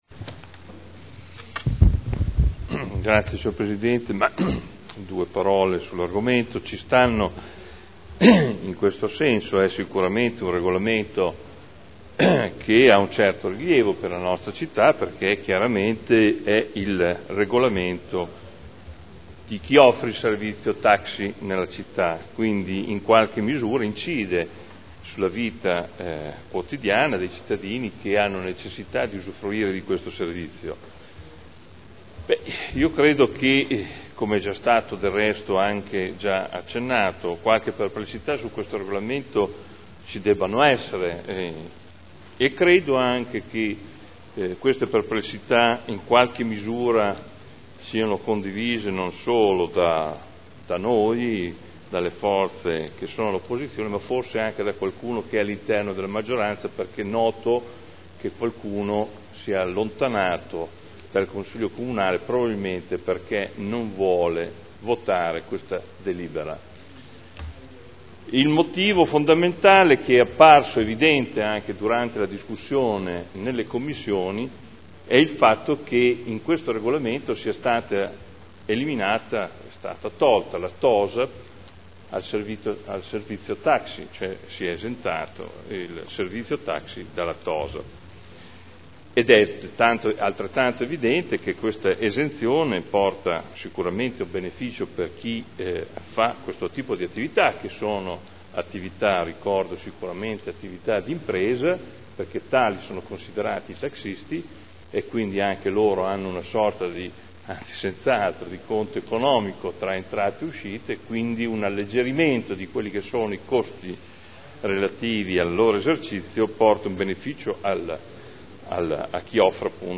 Seduta del 04/04/2011. Dibattito su delibera: Servizi di trasporto pubblico non di linea: taxi e noleggio con conducenti di veicoli fino a 9 posti – Approvazione nuovo Regolamento comunale e modifica art. 28 del Regolamento per l’applicazione della tassa per l’occupazione di spazi ed aree pubbliche e per il rilascio delle concessioni di suolo pubblico (Commissione consiliare del 15 e del 29 marzo 2011)